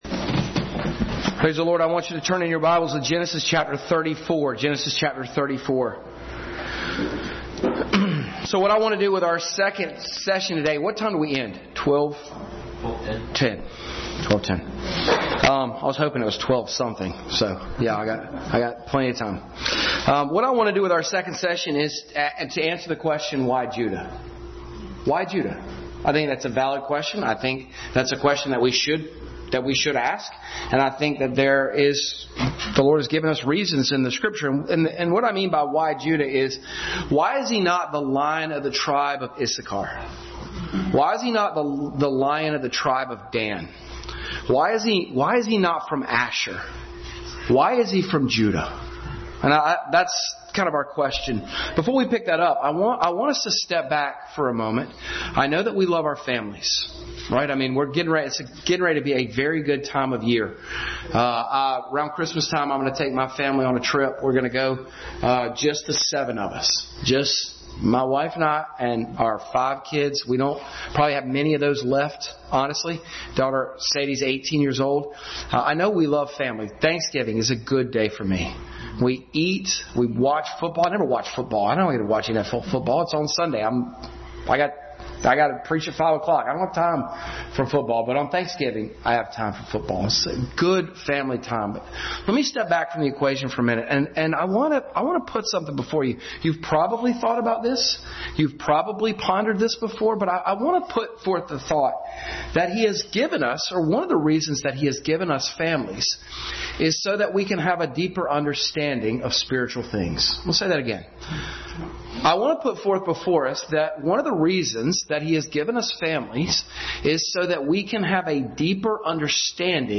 Passage: Genesis 43:9, 44:18-34, 38, 49 Service Type: Family Bible Hour